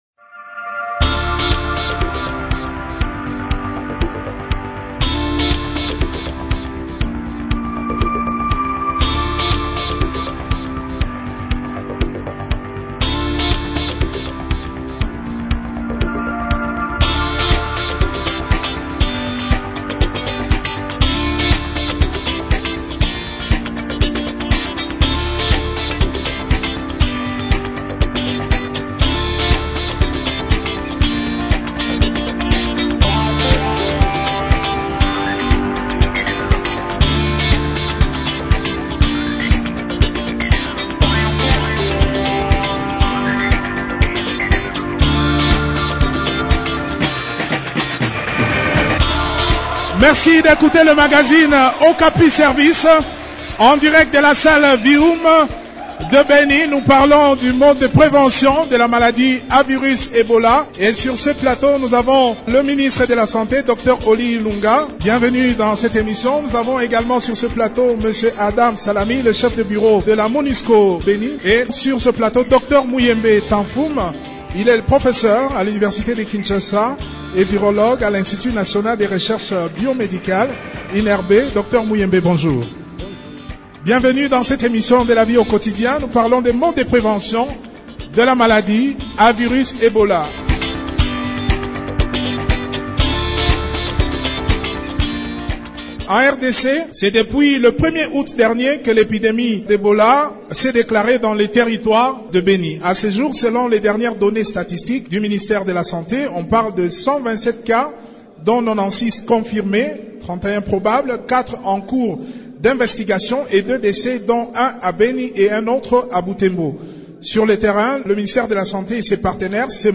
Cette émission publique a été enregistrée dans la salle Vihum de Beni dans le cadre de la campagne de riposte de l'épidémie d'Ebola lancée par le ministère de la Santé publique dans le territoire de Beni.